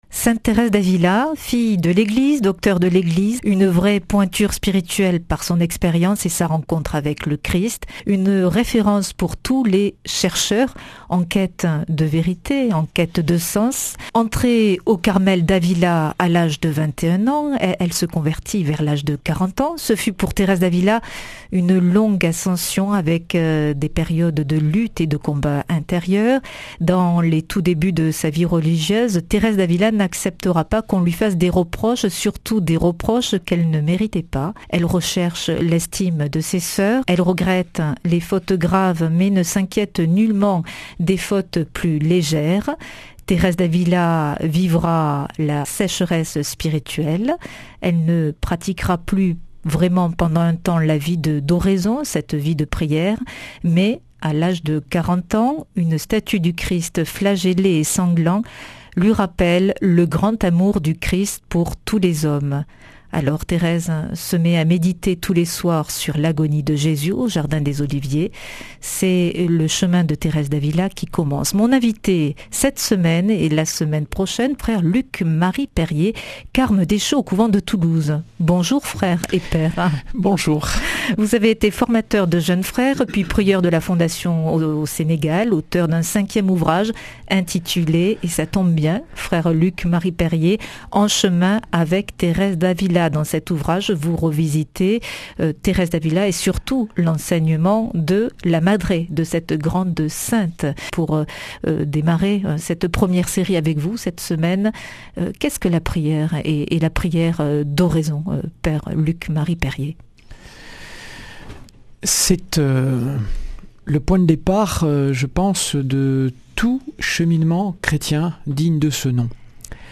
Speech